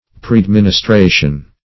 Search Result for " preadministration" : The Collaborative International Dictionary of English v.0.48: Preadministration \Pre`ad*min`is*tra"tion\, n. Previous administration.